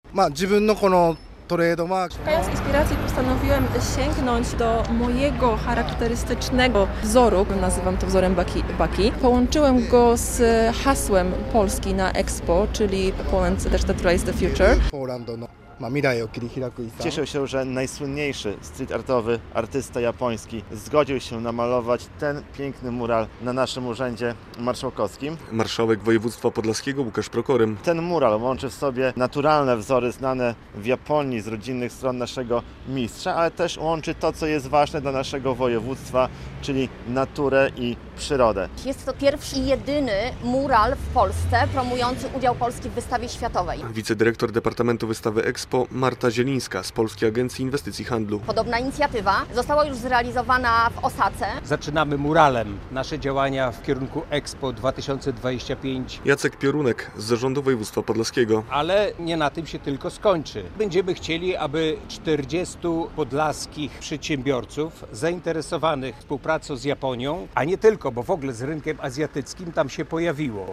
Mural promuje EXPO w Osace - relacja